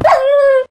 Sound / Minecraft / mob / wolf / death.ogg